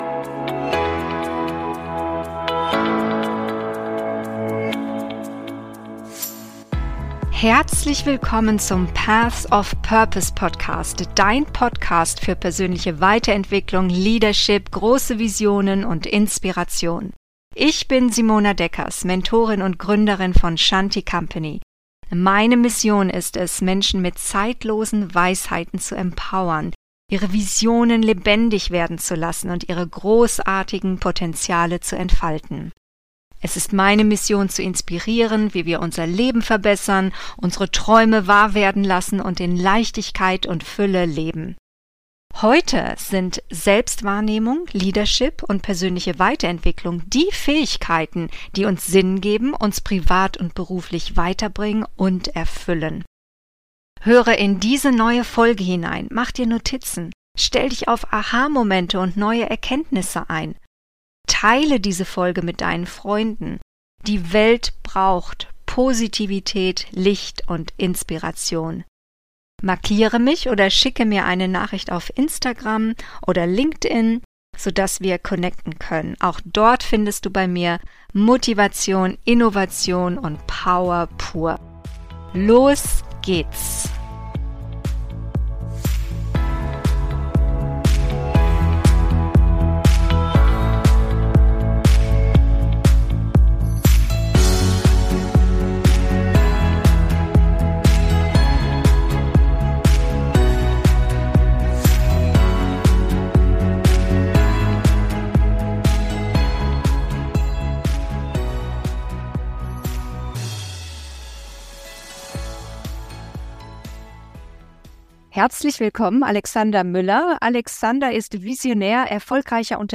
Das eigene Potential entfesseln - Persönlichkeitsentwicklung trifft Unternehmertum - Interview